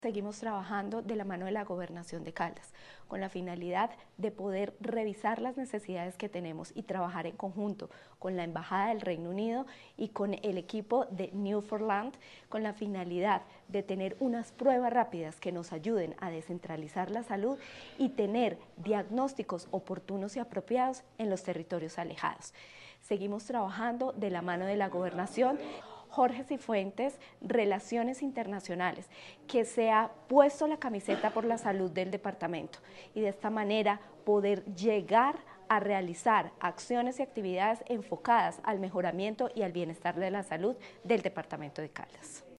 Natalia Castaño Díaz, directora de la DTSC.